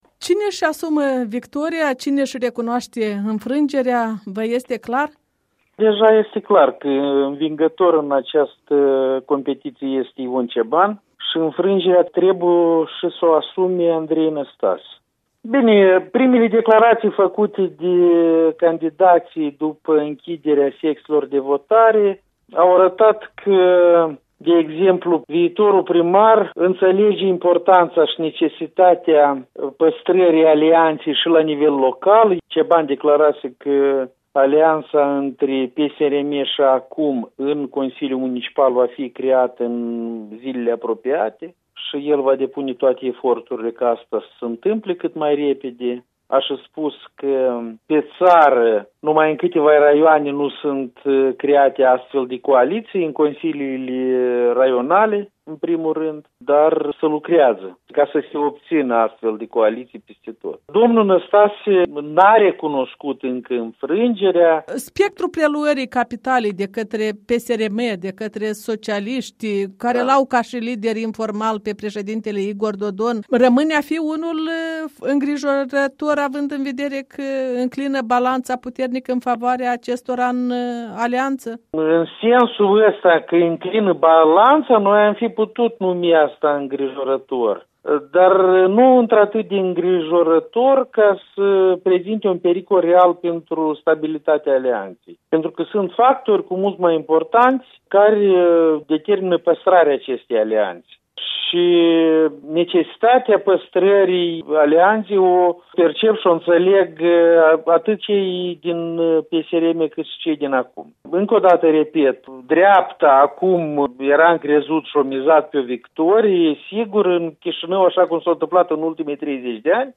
Interviu cu Alexei Tulbure